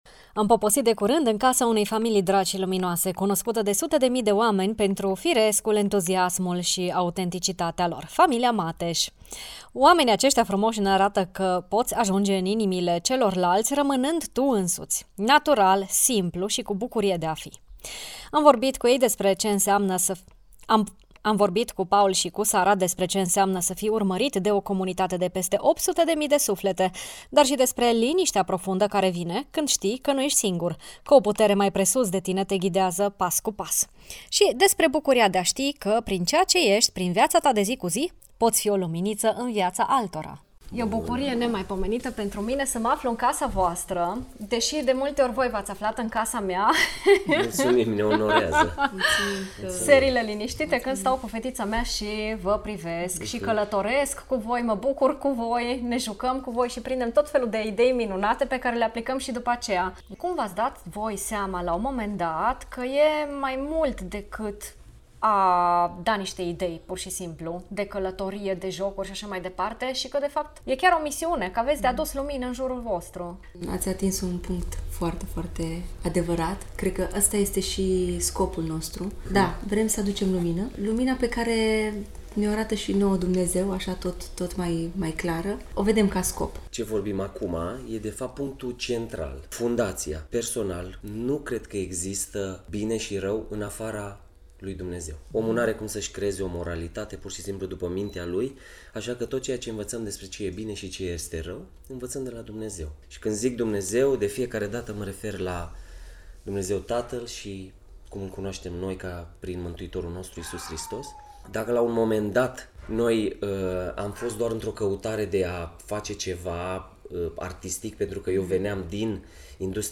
Astăzi o primă parte a interviului: